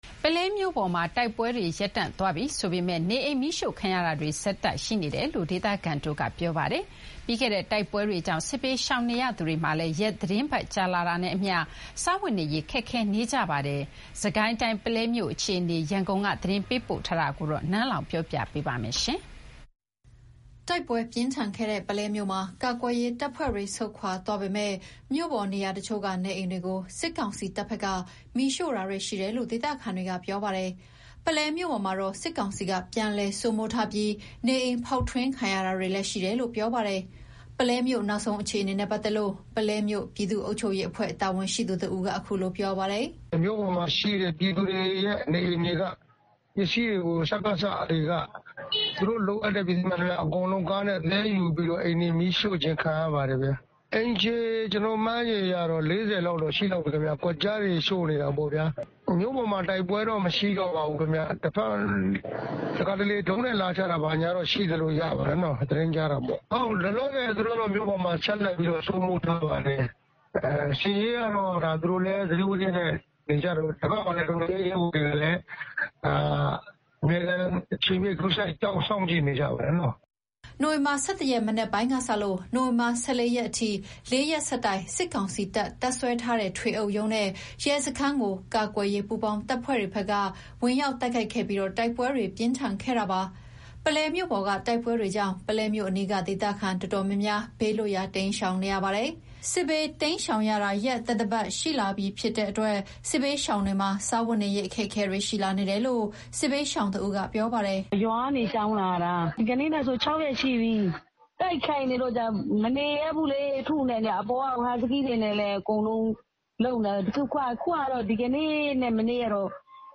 ပုလဲမြို့ပေါ်မှာ တိုက်ပွဲတွေ ရပ်တန့်သွားပြီဆိုပေမယ့်၊ နေအိမ်မီးရှို့ခံရတာတွေ ဆက်ရှိနေတယ်လို့ ဒေသခံတွေက ပြောပါတယ်။ ပြီးခဲ့တဲ့ တိုက်ပွဲတွေကြောင့် စစ်ဘေးရှောင်နေသူတွေမှာလည်း ရက်သီတင်းပတ်ကြာလာတာနဲ့အမျှ စားဝတ်နေရေးခက်ခဲလာကြပါတယ်။ စစ်ကိုင်းတိုင်း ပုလဲမြို့အခြေအနေ ရန်ကုန်က သတင်းပေးပို့ထားတာကို ပြောပြပါမယ်။
နိုဝင်ဘာ ၁၁ ရက် မနက်ပိုင်းကစလို့ နိုဝင်ဘာ ၁၄ ရက်အထိ ၄ ရက်ဆက်တိုက် စစ်ကောင်စီ တပ် တပ်စွဲထားတဲ့ ထွေအုပ်ရုံးနဲ့ရဲစခန်းကို ကာကွယ်ရေးပူးပေါင်းတပ်ဖွဲ့တွေက ဝင်ရောက်တိုက်ခိုက်ခဲ့ပြီး တိုက်ပွဲတွေ ပြင်းထန်ခဲ့တာပါ။ ပုလဲမြို့ပေါ်က တိုက်ပွဲတွေကြောင့် ပုလဲမြို့အနီးက ဒေသခံတော်တော်များများ ဘေးလွတ်ရာ တိမ်းရှောင်နေရပါတယ်။ စစ်ဘေးတိမ်းရှောင်ရတာ ရက်သတ္တပတ်နီးပါးရှိလာတဲ့အတွက် စစ်ဘေးရှောင်တွေမှာ စားဝတ်နေရေးအခက်အခဲတွေရှိလာနေတယ်လို့ စစ်ဘေးရှာင်တဦးက ပြောပါတယ်။